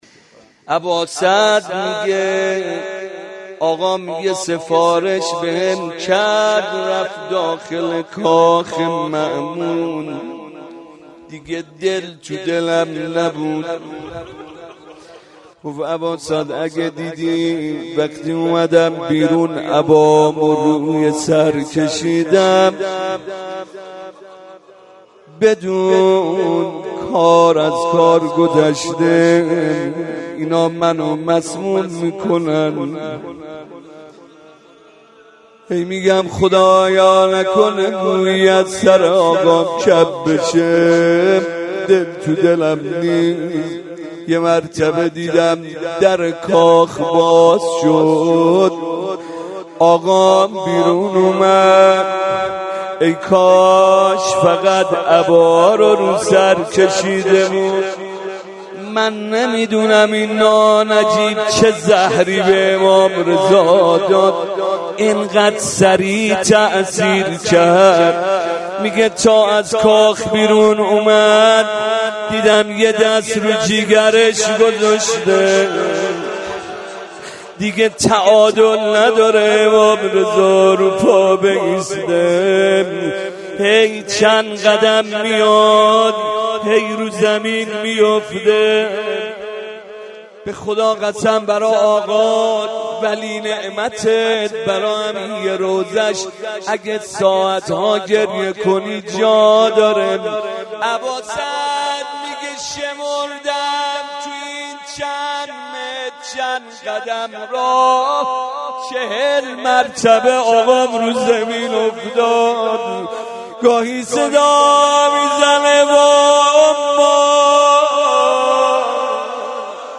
روضه
02.rozeh.mp3